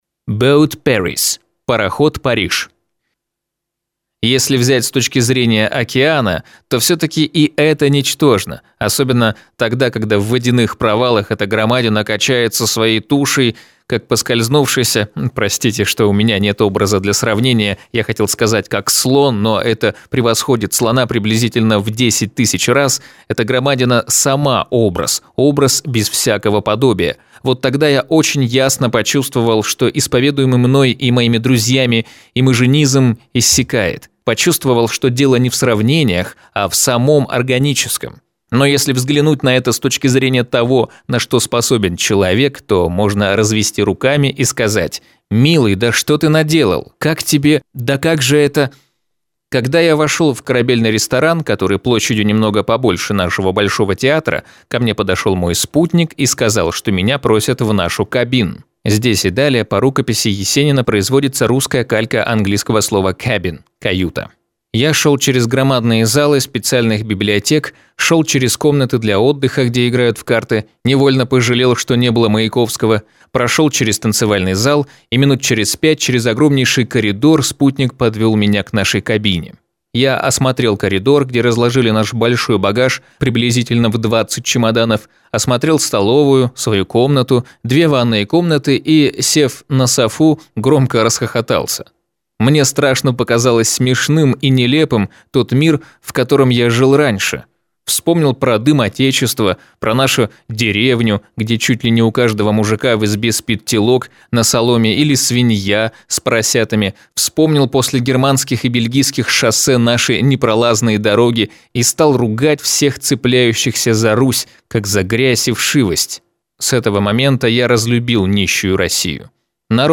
Аудиокнига Железный Миргород (очерки об Америке) | Библиотека аудиокниг